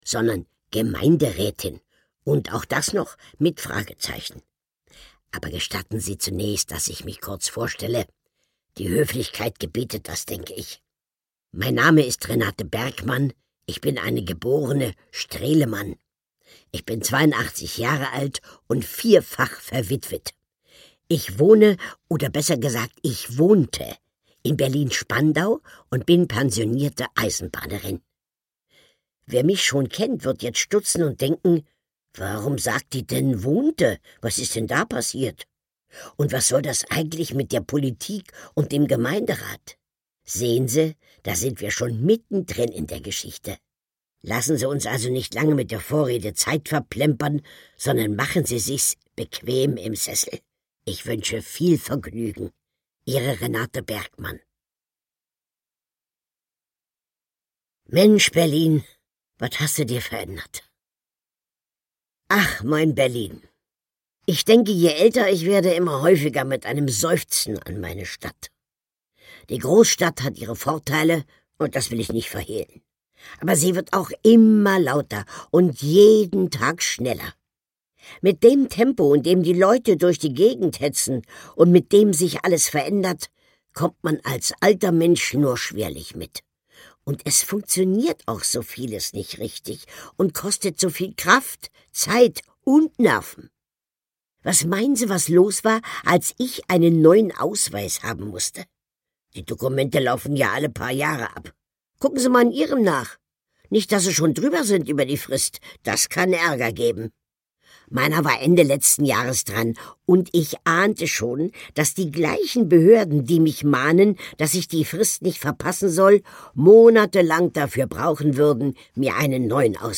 Lesung mit Carmen-Maja Antoni (5 CDs)
Carmen-Maja Antoni (Sprecher)